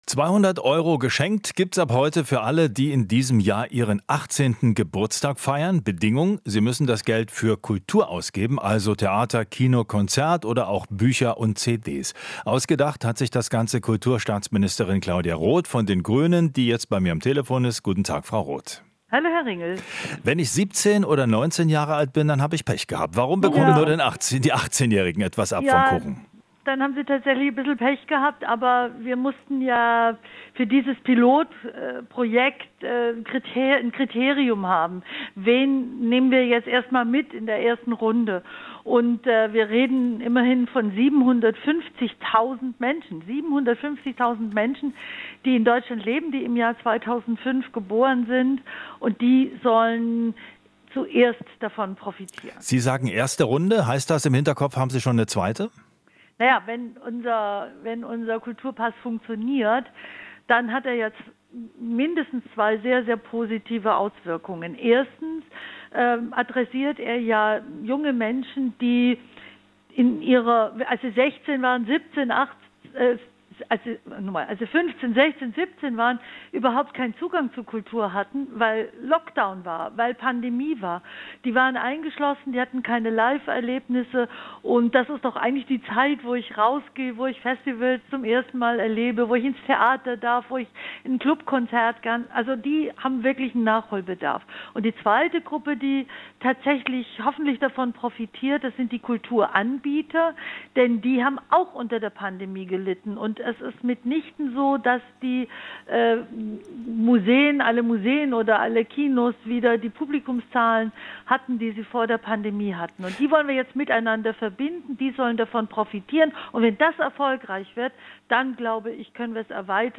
Inforadio Nachrichten, 30.07.2023, 16:00 Uhr - 30.07.2023